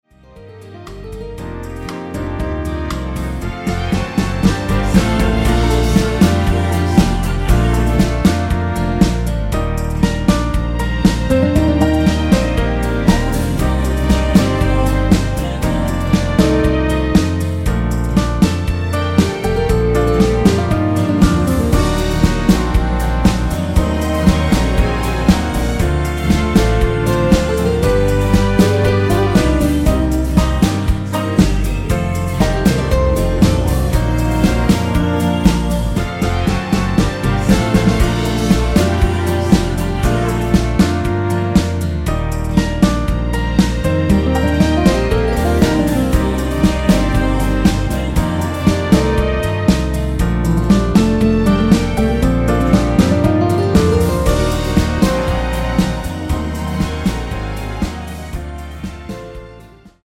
(-2) 내린 코러스포함된 MR 입니다.(미리듣기 참조).
Bb
앞부분30초, 뒷부분30초씩 편집해서 올려 드리고 있습니다.
중간에 음이 끈어지고 다시 나오는 이유는